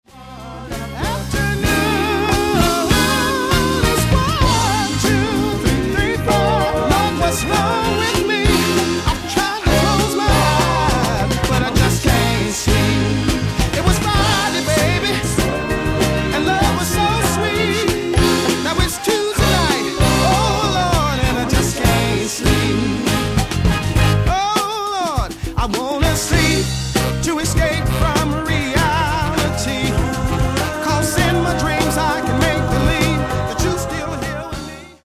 Genere:   Disco | Funky